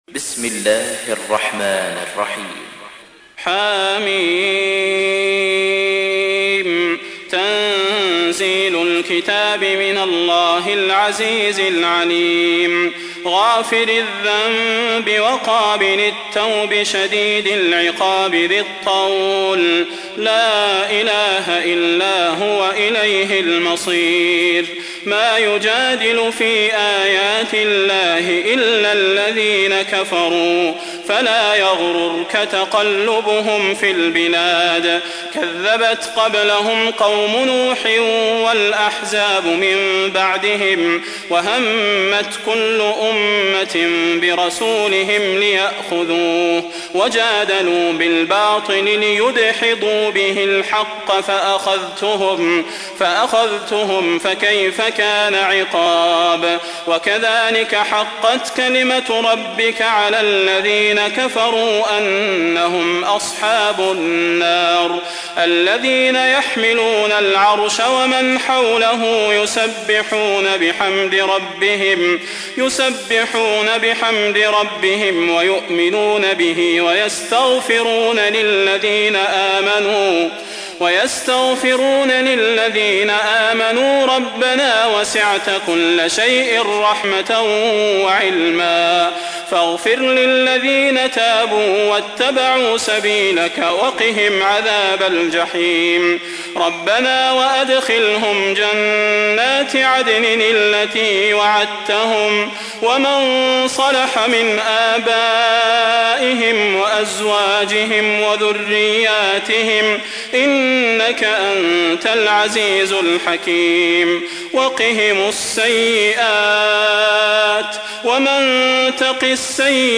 تحميل : 40. سورة غافر / القارئ صلاح البدير / القرآن الكريم / موقع يا حسين